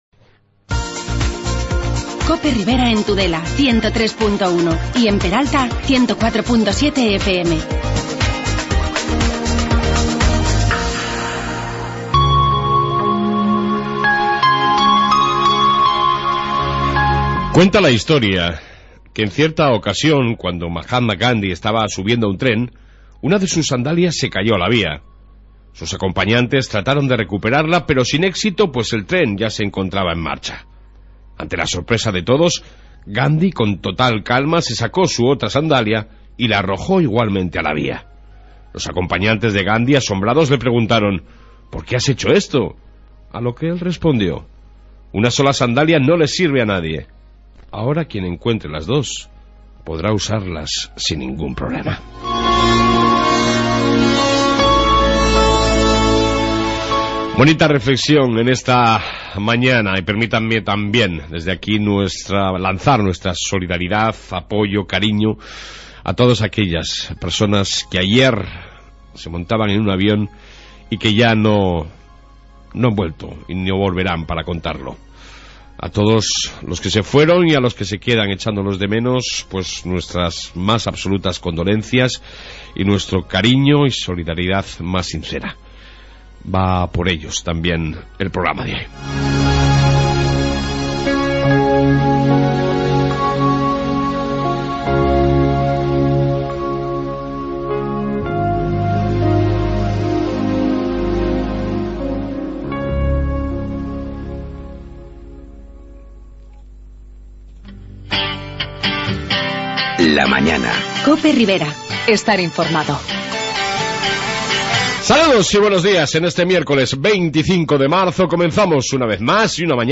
AUDIO: En esta 1 parte podeís encontrar la Reflexión del día, noticias y entrevista con el Alcalde Luis casado...